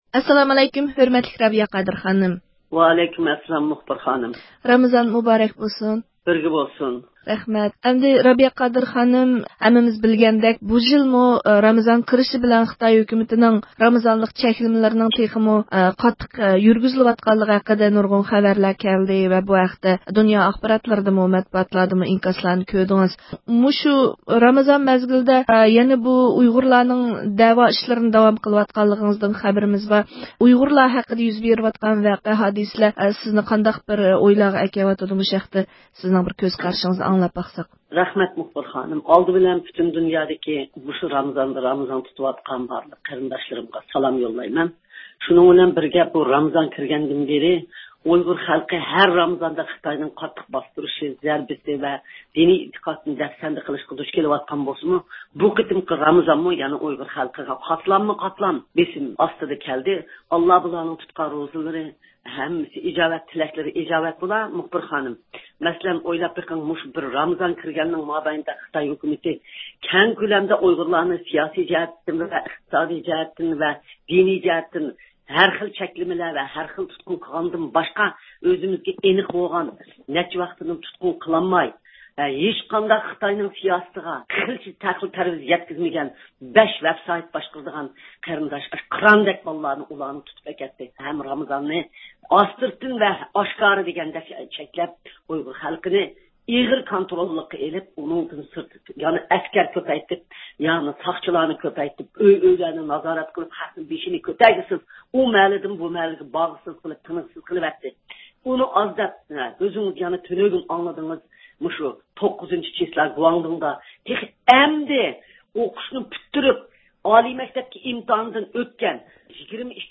بۇ خىل سىياسىي بېسىمدىن باشقا يەنە، رامىزان باشلانغاندىن بۇيان ئۇيغۇر خەلقىنىڭ بېشىغا كېلىۋاتقان يەر تەۋرەش، پەۋقۇلئاددە زور كەلكۈن ئاپەتلىرىگە ئوخشاش تۈرلۈك تەبىئىي ئاپەت ۋە كۈلپەتلەردىن ئەندىشىلەنگەن دۇنيا ئۇيغۇر قۇرۇلتىيىنىڭ رەئىسى رابىيە قادىر خانىم، مەخسۇس زىيارىتىمىزنى قوبۇل قىلىپ، سىياسىي ۋە تەبىئىي ئاپەتلەردە قېلىۋاتقان ئۇيغۇر ئېلىدىكى خەلقتىن ئالاھىدە ھال سورايدىغانلىقىنى، ئۇلار ئۈچۈن دۇئا قىلىدىغانلىقىنى بىلدۈردى ۋە پۈتۈن دۇنيادىكى ئۇيغۇرلارنى ئۇلۇغ ئاي، ئۇلۇغ كۈنلەردە ئۆزىنىڭ مۇسۇلمانلىق ۋە ئۇيغۇرلۇق بۇرچىنى ئادا قىلىشقا، قۇربىنىڭ يېتىشىچە ياردەمگە موھتاج ئۇيغۇرلارغا ياردەم قولىنى سۇنۇشقا چاقىردى.